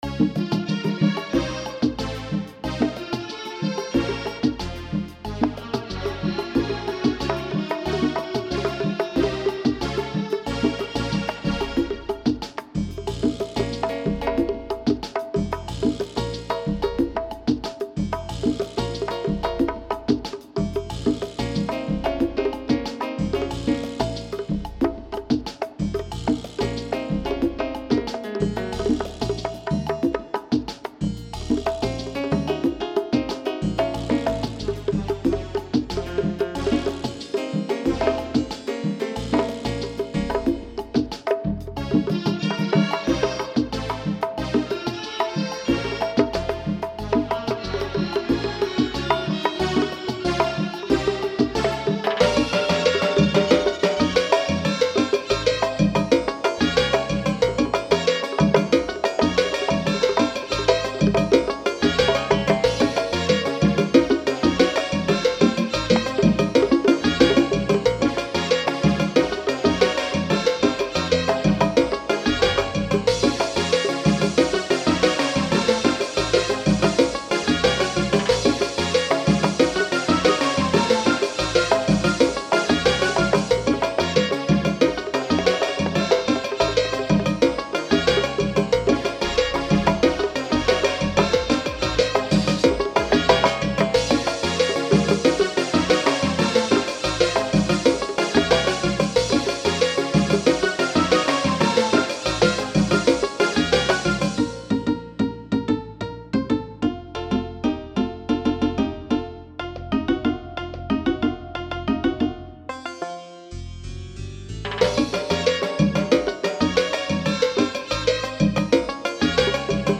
It's a Latin fusion instrumental style.
PS. I just got some drum mics and hope to re do the background rumba conga part and timbales again by tomorrow.